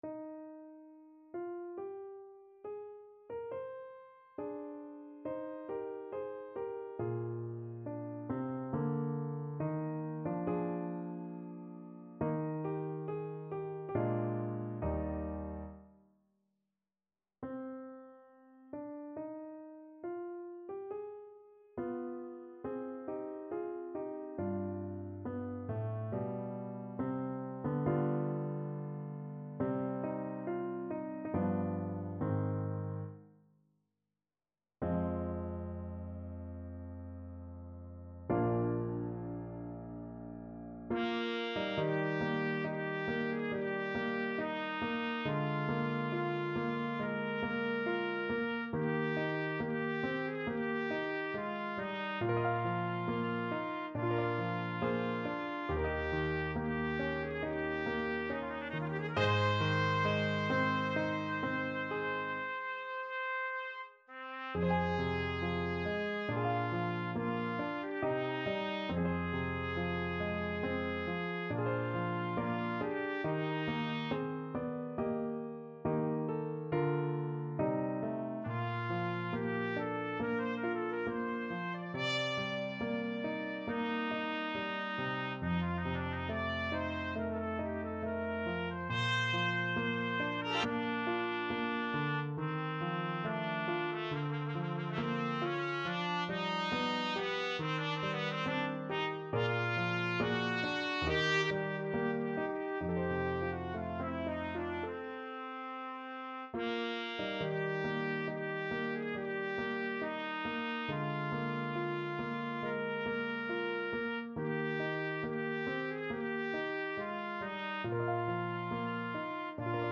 Trumpet version
4/4 (View more 4/4 Music)
Larghetto (=80) =69
A4-D6
Classical (View more Classical Trumpet Music)